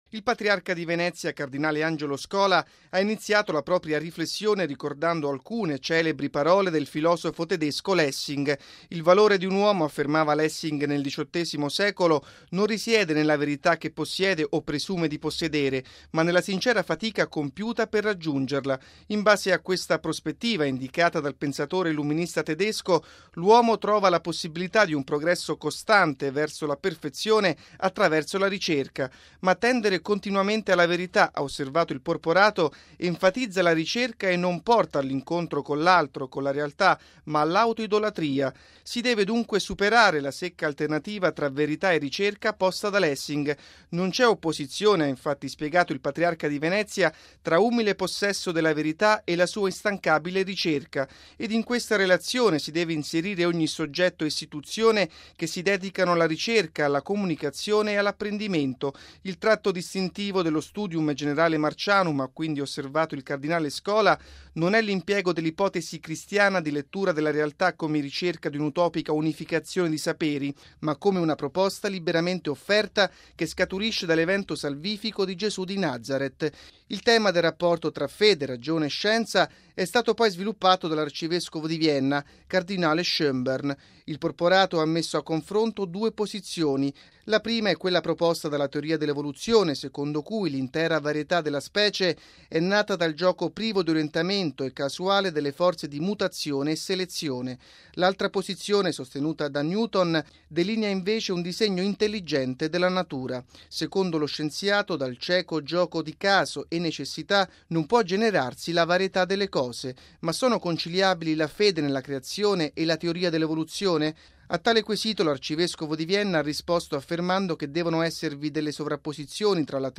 Il dibattito sull’evoluzionismo e sul rapporto tra fede, ragione e scienza è stato al centro stamani, nella Basilica della Salute a Venezia, della prolusione dell’arcivescovo di Vienna, cardinale Christoph Schönborn.